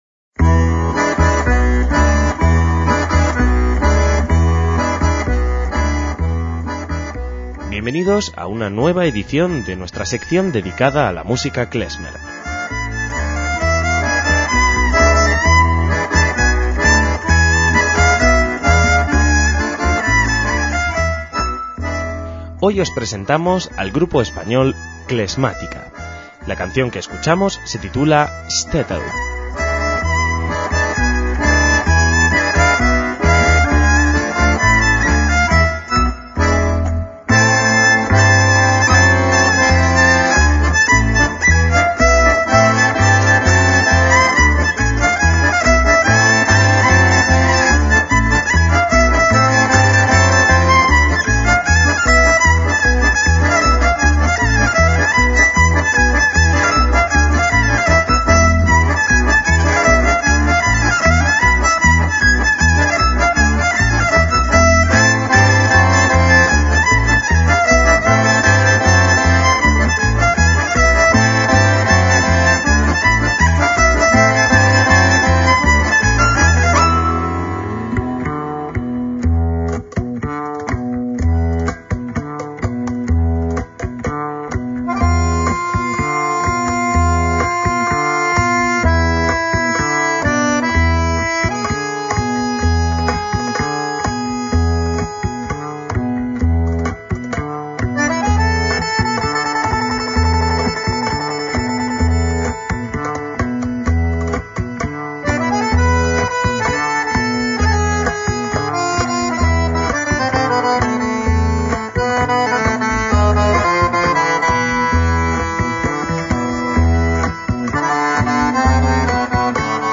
MÚSICA KLEZMER
está integrado por músicos de Polonia y España
acordeón
violín
batería y percusión
contrabajo